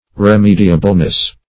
remediableness - definition of remediableness - synonyms, pronunciation, spelling from Free Dictionary
[1913 Webster] -- Re*me"di*a*ble*ness , n. - Re*me"di*a*bly , adv.